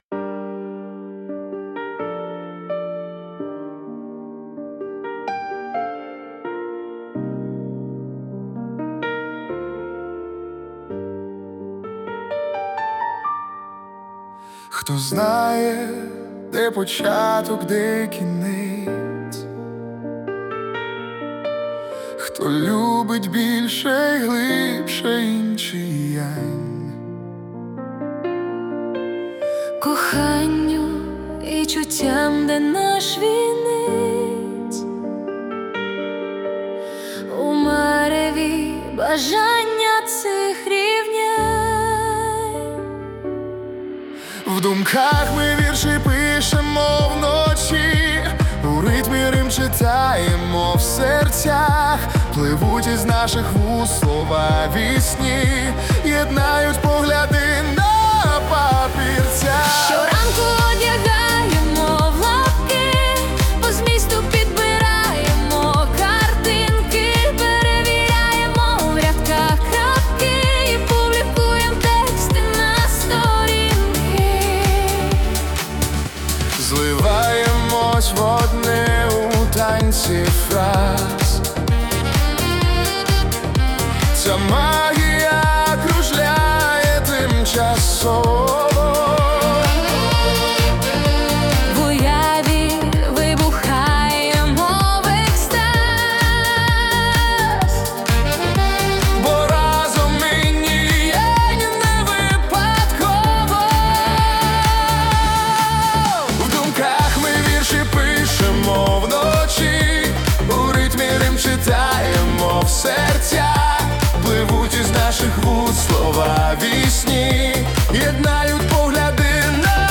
Музика і вокал ШІ - SUNO AI v4.5+